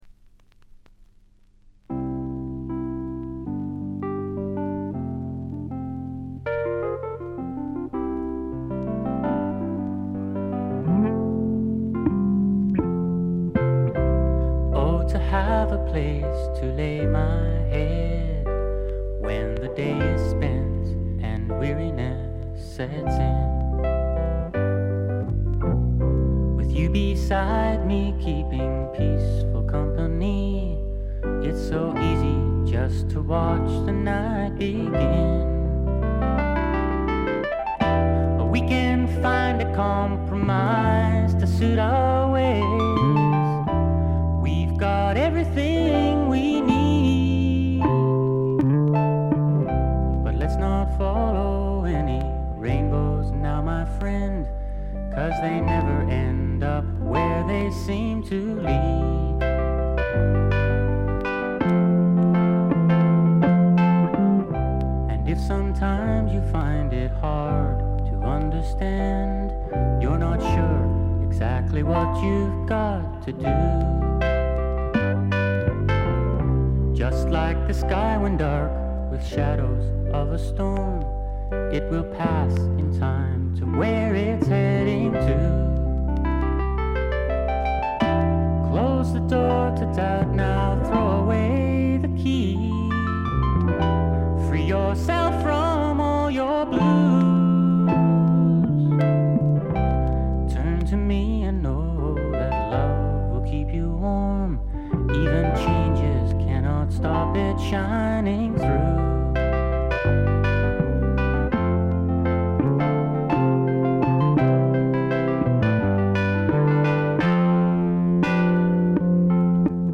ドラムレスで、Heron的な木漏れ日フォークのほんわか感と、米国製メロー・フォーク的なまろやかさが同居した名作です。
試聴曲は現品からの取り込み音源です。